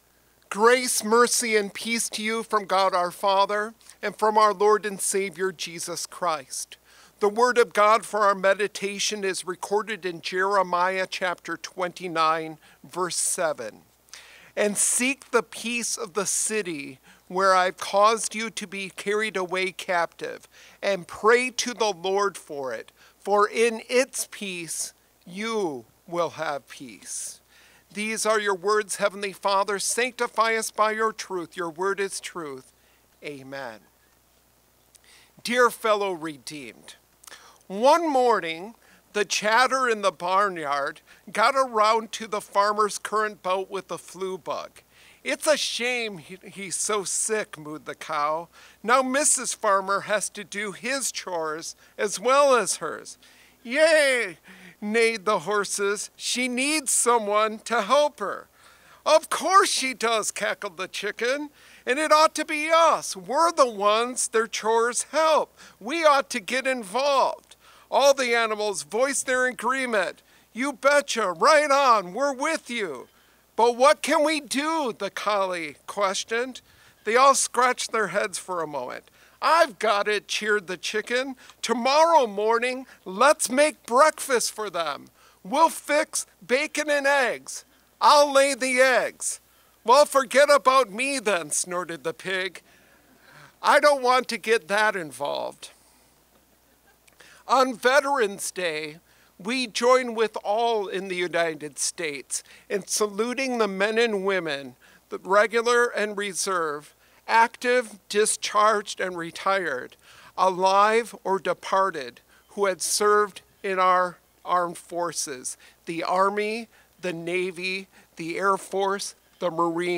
Complete service audio for Chapel - Tuesday, November 11, 2025